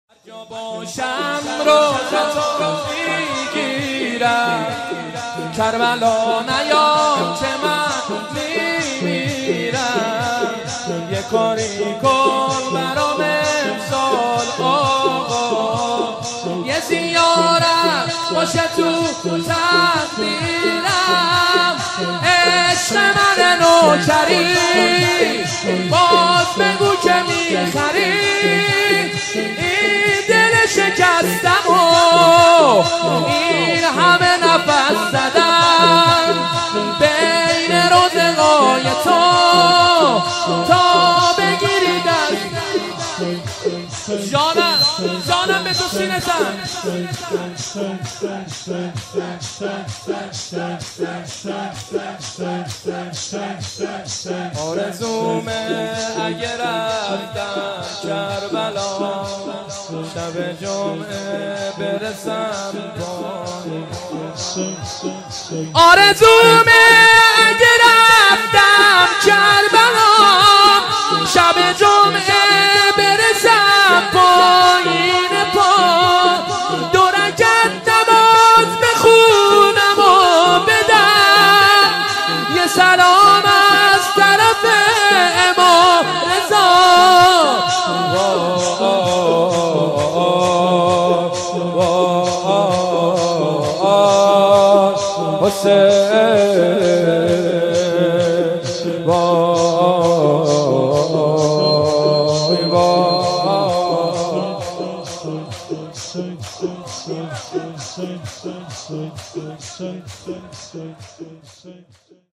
مناسبت : شب بیست و سوم رمضان - شب قدر سوم
قالب : شور